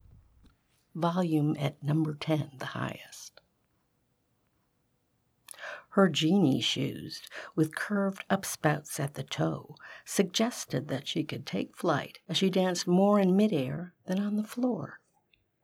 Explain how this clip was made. Here’s #10 again, this time I’ve played around with noise reduction.